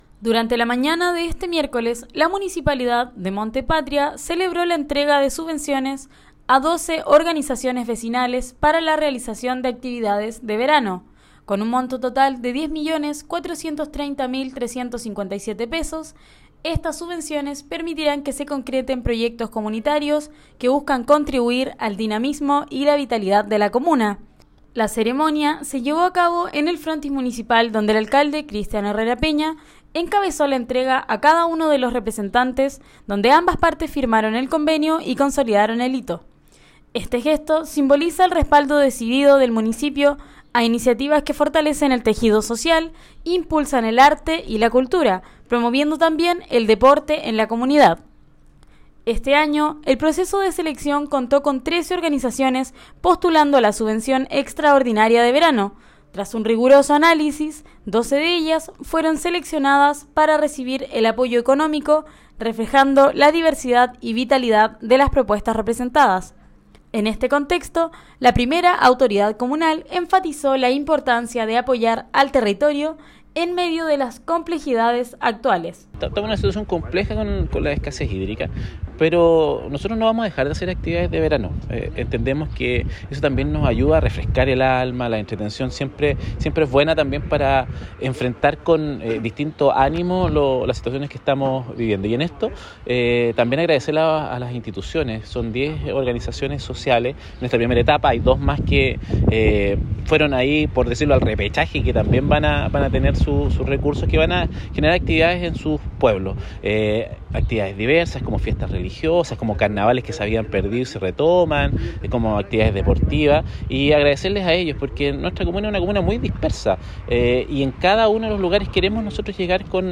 DESPACHO-SUBVENCION-MUNICIPAL-ACTIVIDADES-DE-VERANO.mp3